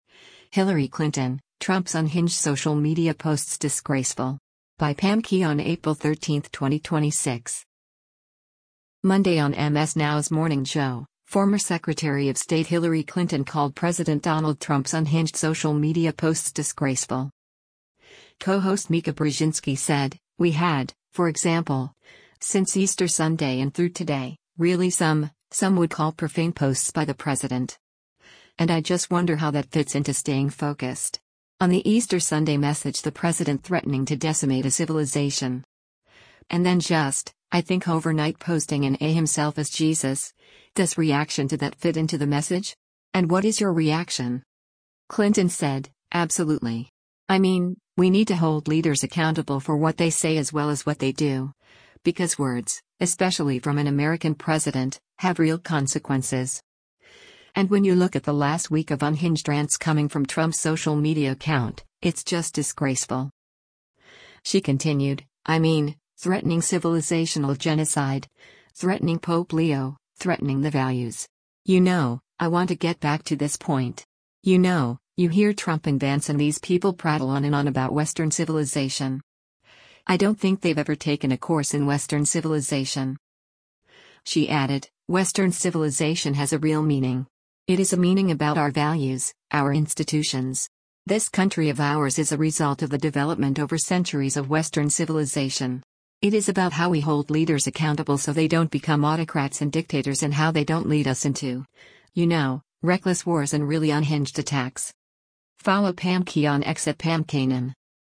Monday on MS NOW’s “Morning Joe,” former Secretary of State Hillary Clinton called President Donald Trump’s “unhinged” social media posts “disgraceful.”